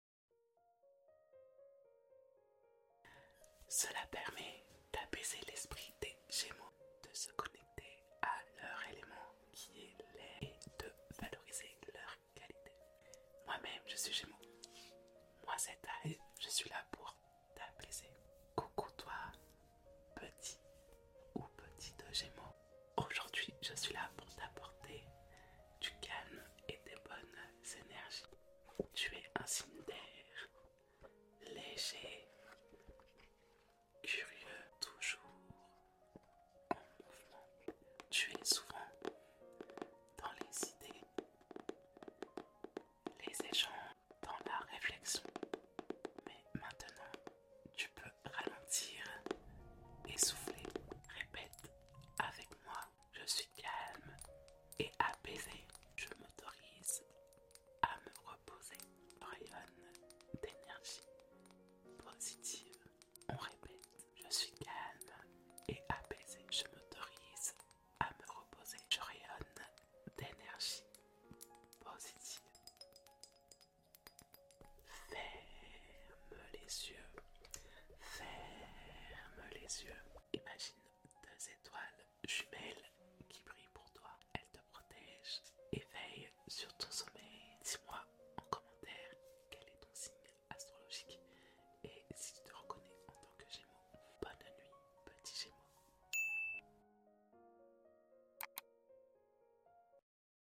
🌸 ASMR POUR LES GÉMEAUX sound effects free download